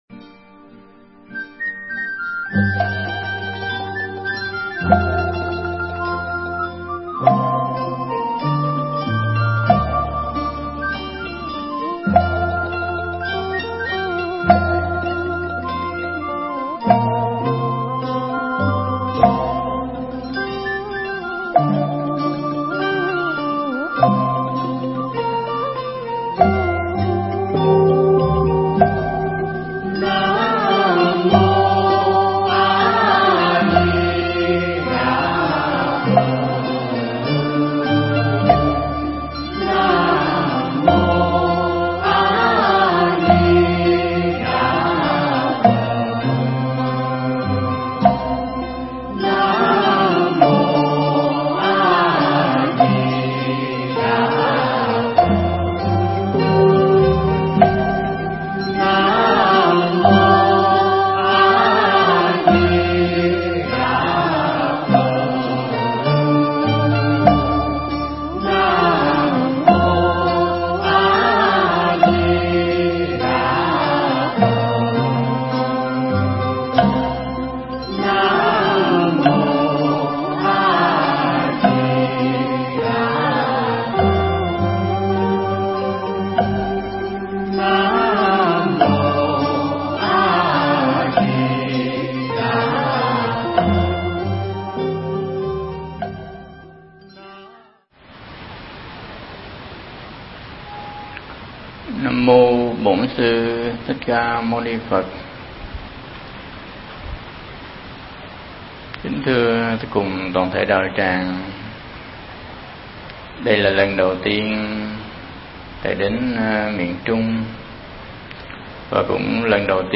Thuyết pháp Lời Phật Dạy
Mp3 Pháp Thoại Lời Phật Dạy
thuyết giảng tại Chùa Pháp Bảo, Hoa Kỳ